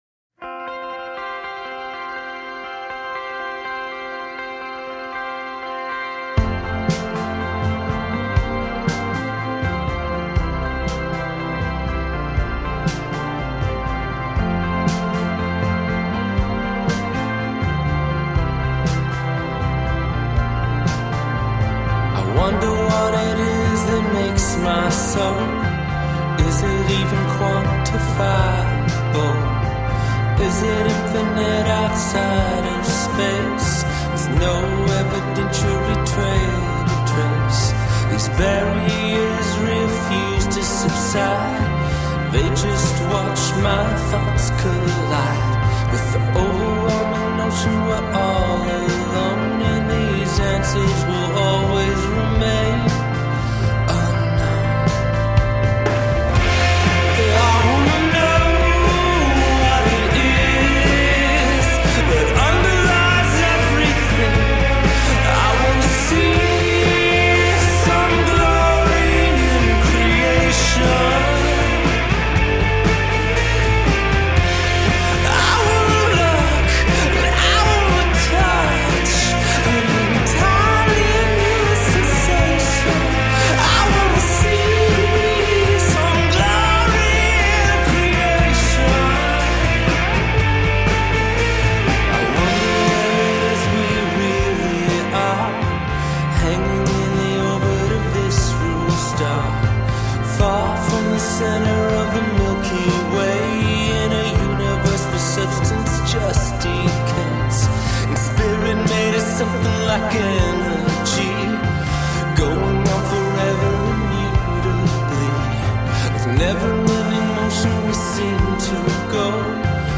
Hook-laden anthems of survival and triumph.
Tagged as: Electro Rock, Alt Rock, Darkwave, Downtempo, Goth